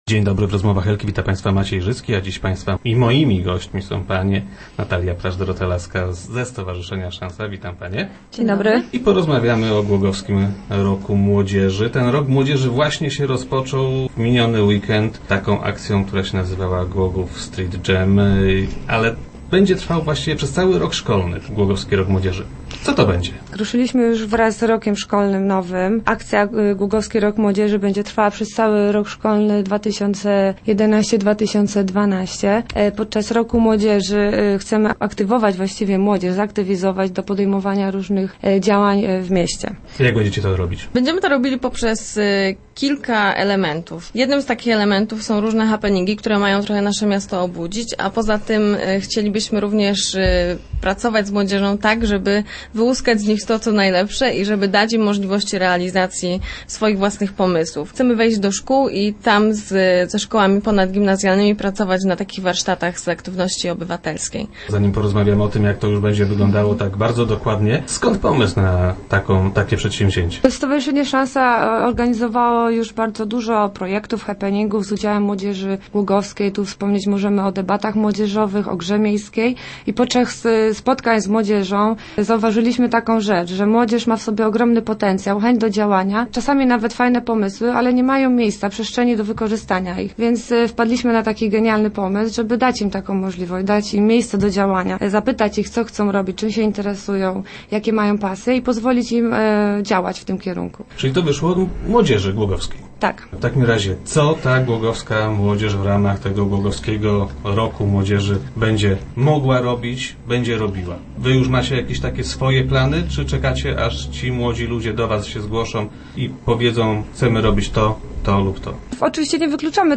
Chcemy dać młodym ludziom możliwość realizacji swych własnych pomysłów - mówiły przedstawicielki stowarzyszenia Szansa.